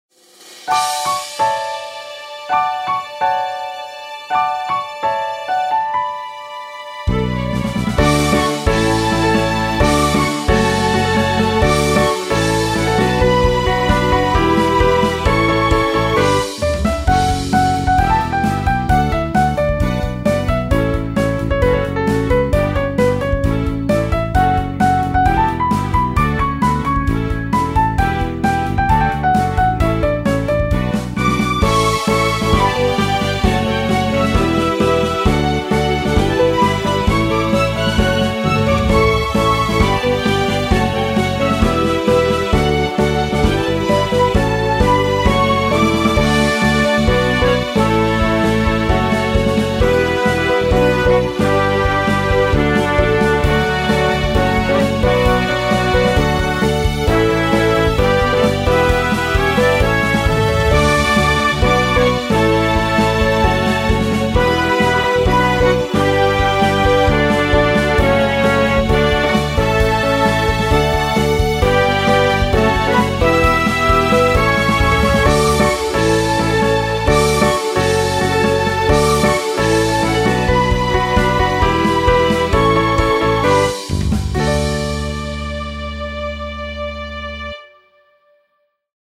静かなピアノから入り、一気にストリングスで盛り上げていく、明るくノリのいいBGMです。